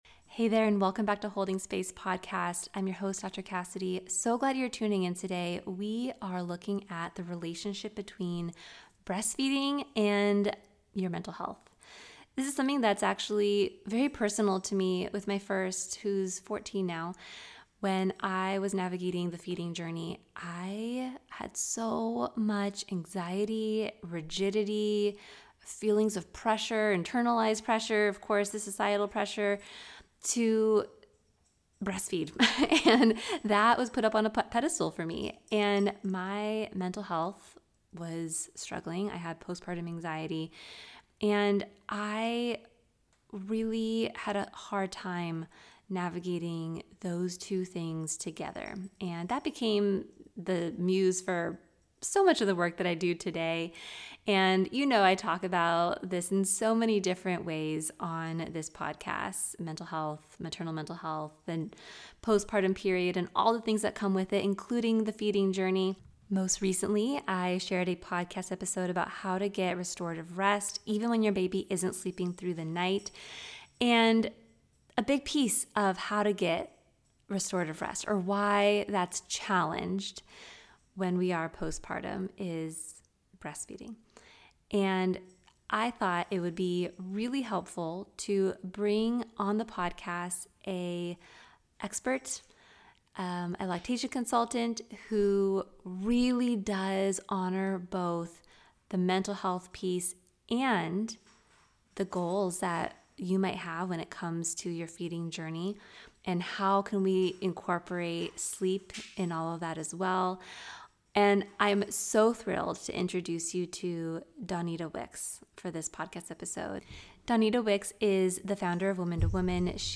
This conversation tackles the gray areas, legal myths, and shame therapists face when paving their own path to off…